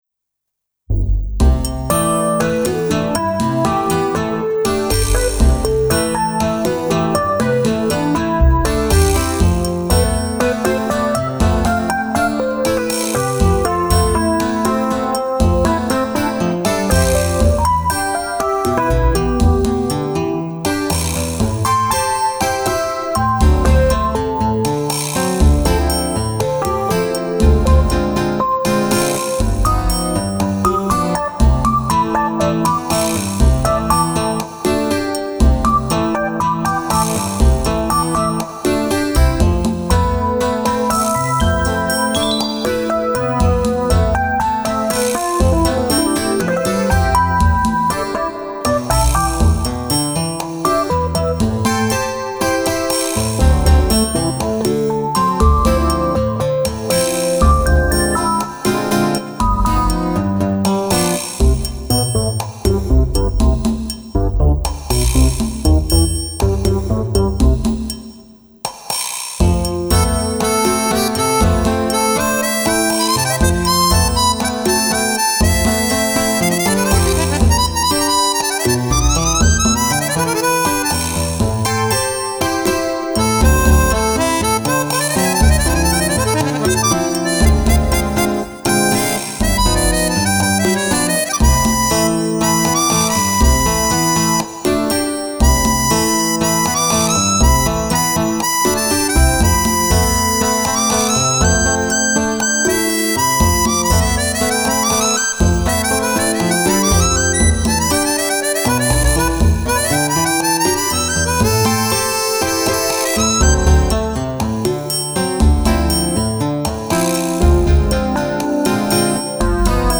音源は、ＳＣ８８２０を購入したので、さらに良くなっているはず。
そんな水が日光にキラキラしているイメージ。